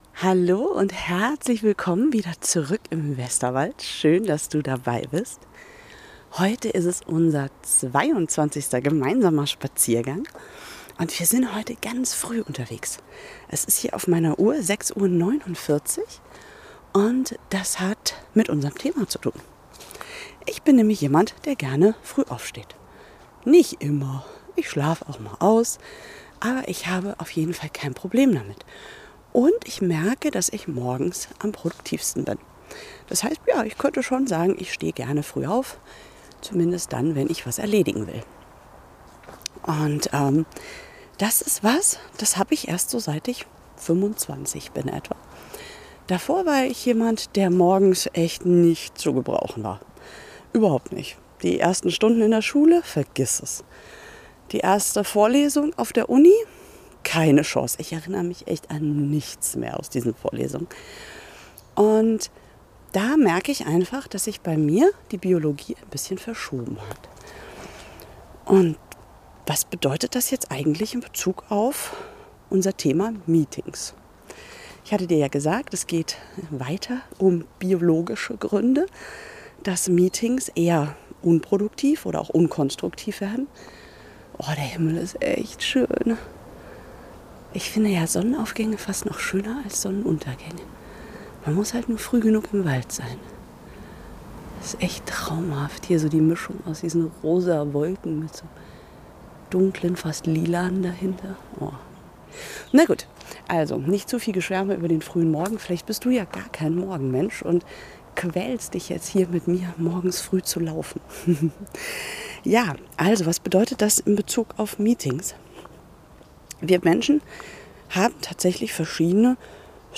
Ich stehe heute um 6:49 Uhr im Wald, höre die Vögel singen und bin topfit - als typische "Lerche".